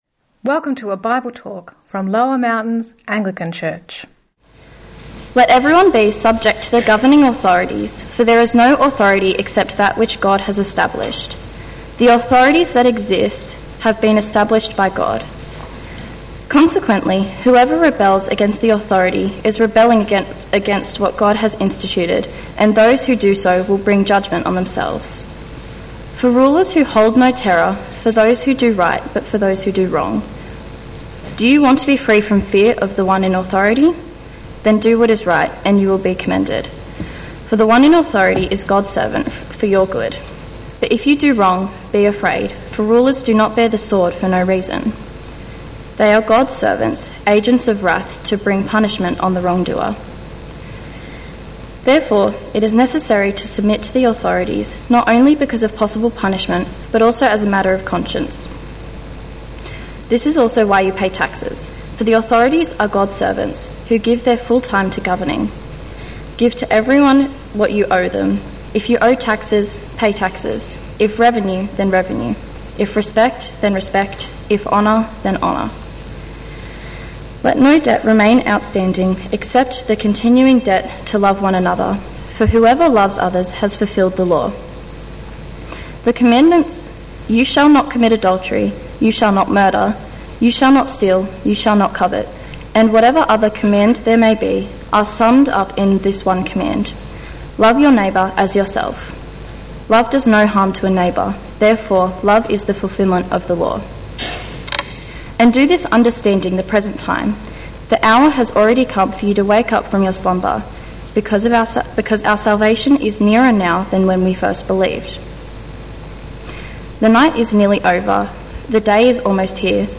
Sermon – Living Loving Sacrifice: submission (Romans 13)